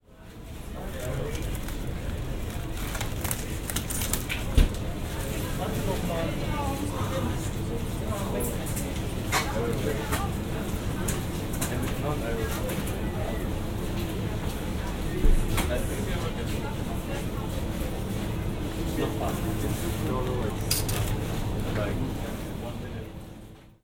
So gut klingt 3D-Sound mit dem Sennheiser Ambeo Smart Headset
Supermarkt
Alle Geräusche sind erstaunlich klar zu lokalisieren.
Bewegungen einer Schallquelle im Raum wirken viel greifbarer.
sennheiser_ambeo_smart_headset_test__supermarkt.mp3